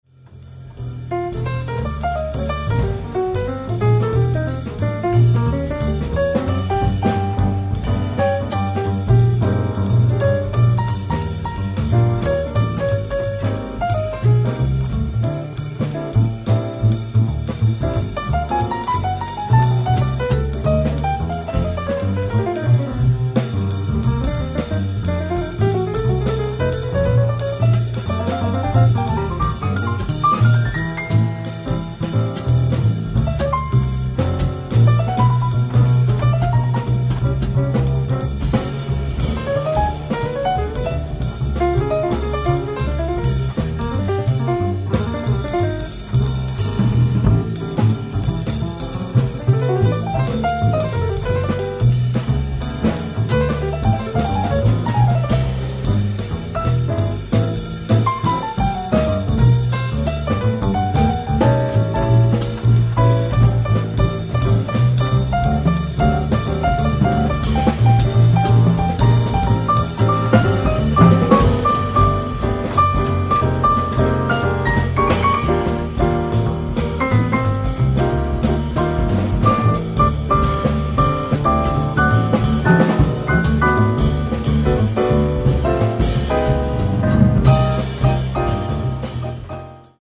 ディスク１＆２：ジャズキャンパス、バーゼル、スイス 11/07/2020
オフィシャル・クラスの極上クオリティー！！
※試聴用に実際より音質を落としています。